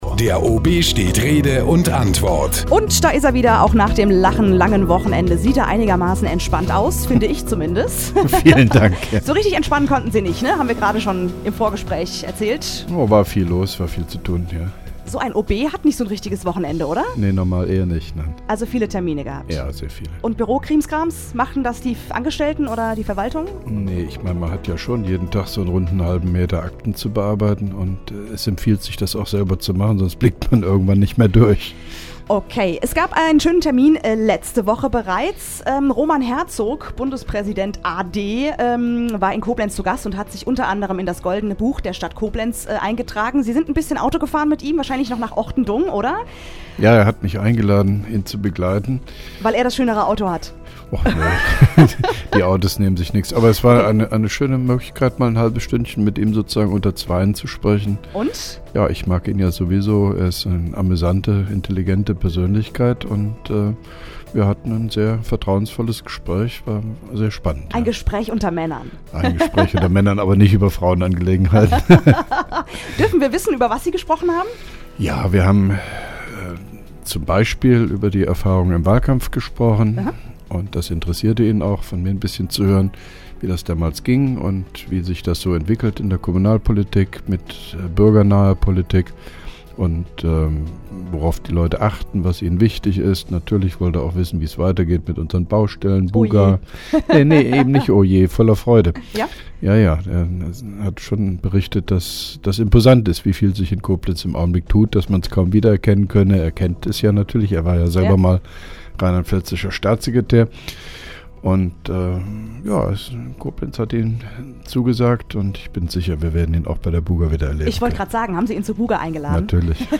(1) Koblenzer OB Radio-Bürgersprechstunde 2.11.2010